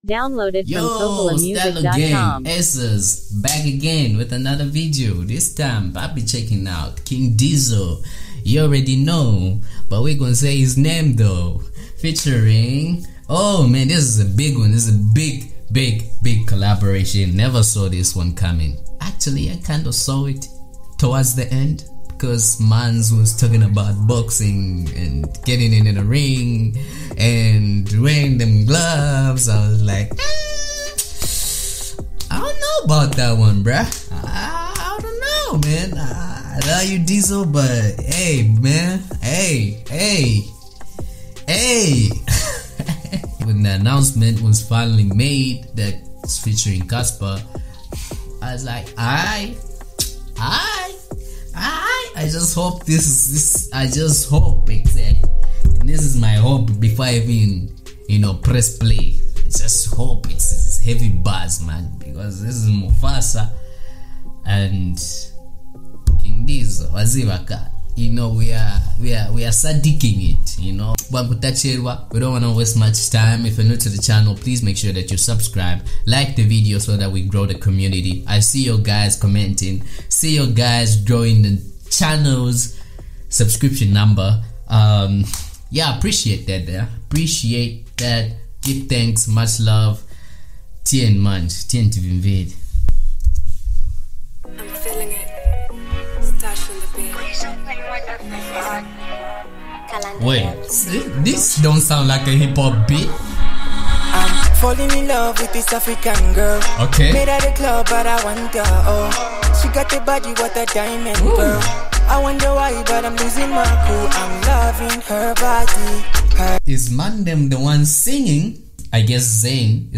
bringing together Zambian and South African vibes.
expect some slick bars and a catchy hook .